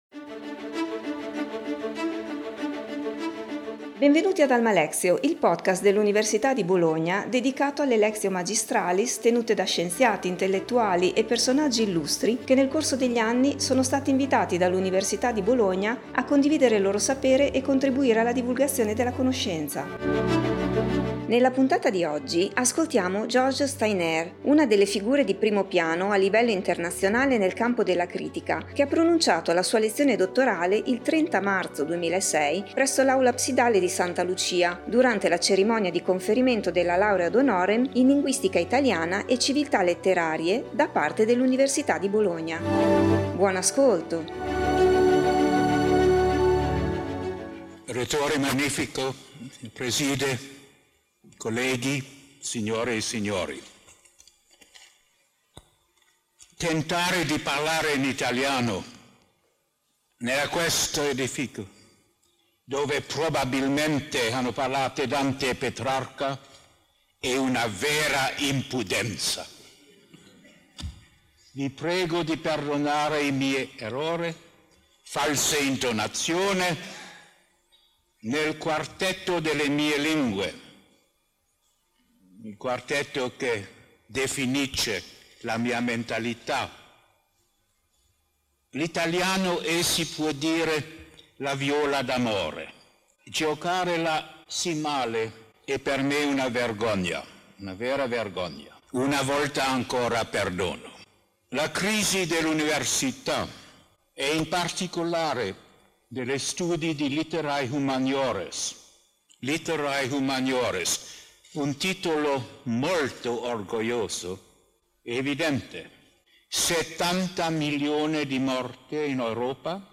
Nella sua attività ha scritto numerosi saggi e studi di grande valore su molti temi: dalla linguistica all’etica, dalla teoria della traduzione alla letteratura, dalle arti figurative alla scienza. L’Università di Bologna gli ha conferito la laurea ad honorem in Linguistica italiana e civiltà letterarie durante la cerimonia tenuta martedì 30 maggio 2006 presso l’Aula Absidale di Santa Lucia.